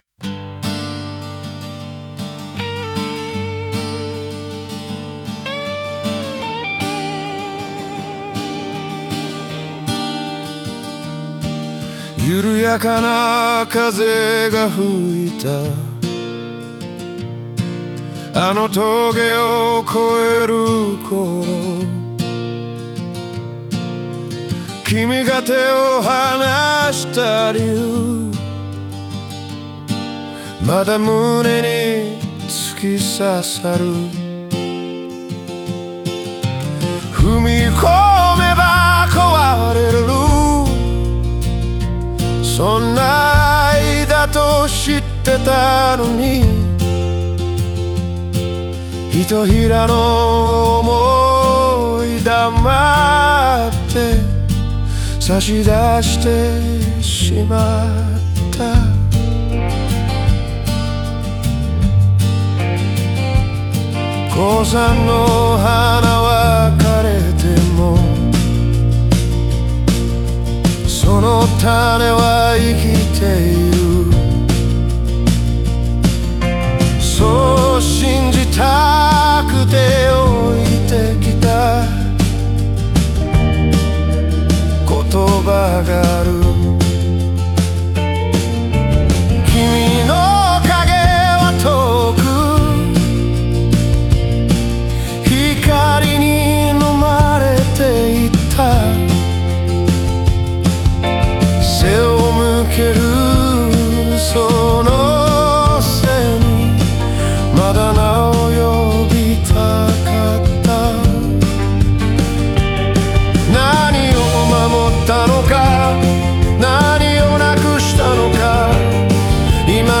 オリジナル曲♪
言えなかった「愛してた」という言葉に込められた、深い葛藤と優しさが静かに響くバラード。